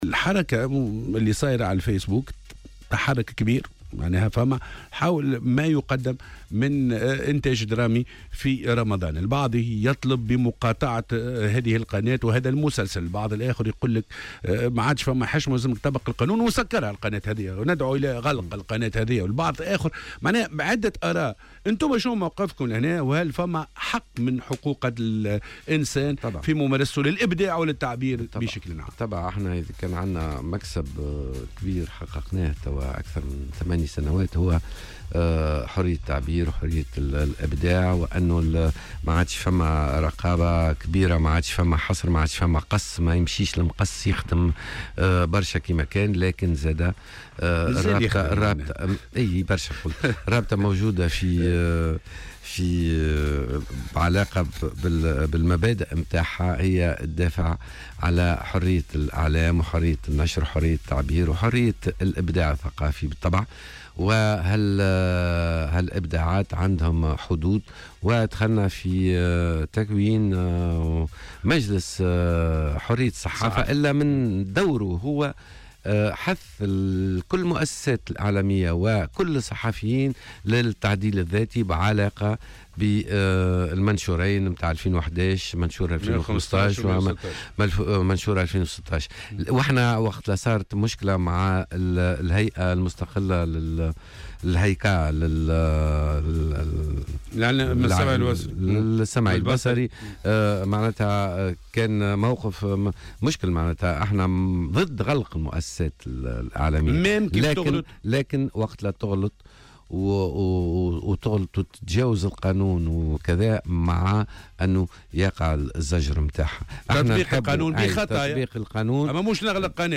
وأوضح في مداخلة له اليوم في برنامج "بوليتيكا" أنه كان من الأجدر تنبيه المشاهدين من وجود مشاهد عنيفة ووضع علامة أسفل الشاشة تفيد بأن الحلقة ممنوعة على الأطفال.